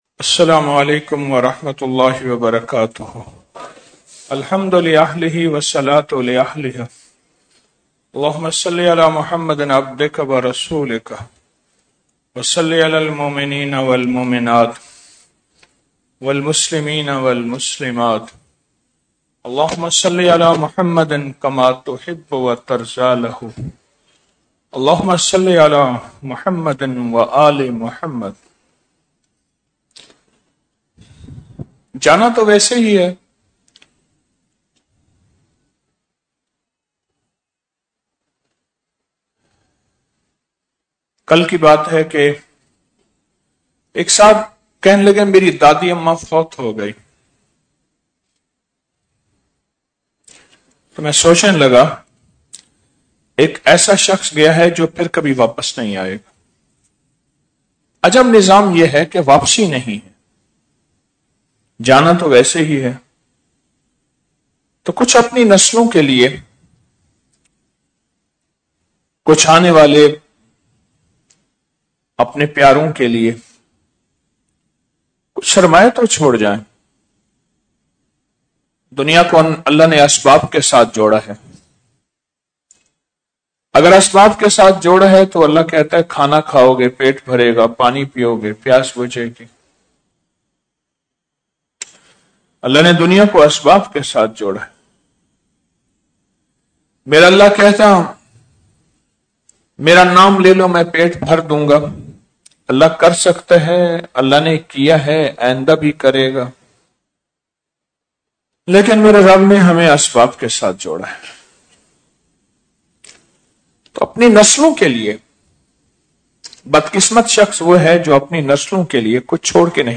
شب جمعہ محفل ! 17 جولائی2025ء - آڈیو درس VOL_0769_DT_17_07_25.mp3 اس درس کو ڈاؤنلوڈ کرنے کے لۓ یھاں کلک کریں صدقہ جاریہ کی نیت سے شیئر کریں چند مزید درس کاش!